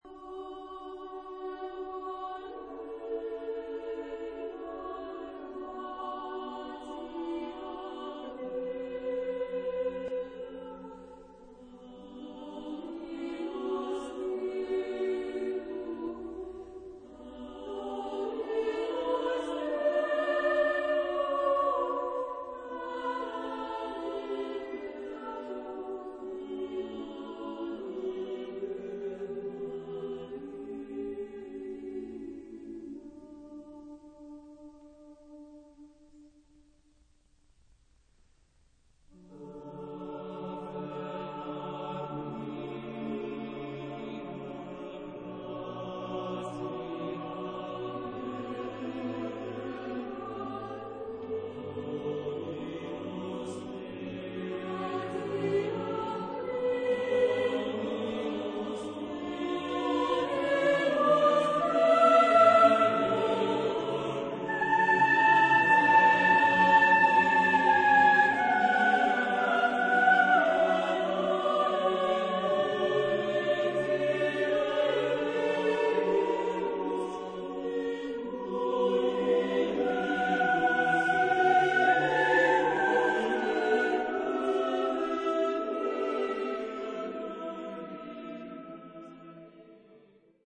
SATB (4 voces Coro mixto) ; Partitura general.
Motete.